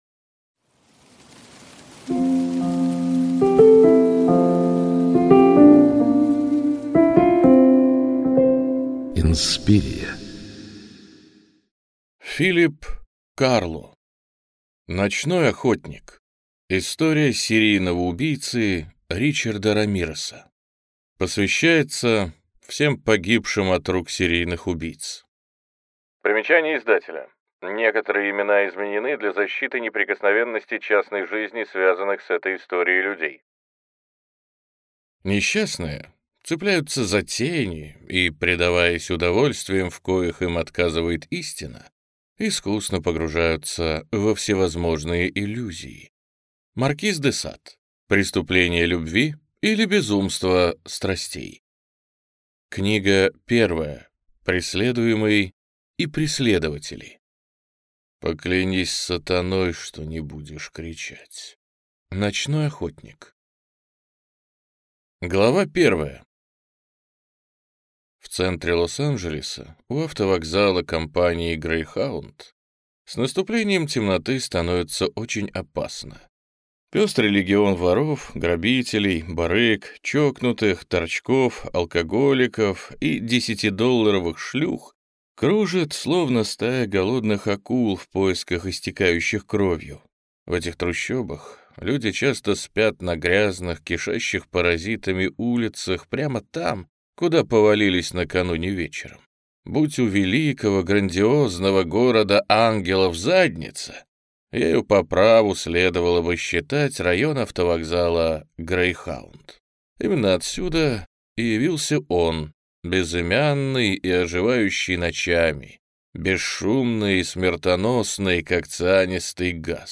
Аудиокнига Ночной охотник. История серийного убийцы Ричарда Рамиреса | Библиотека аудиокниг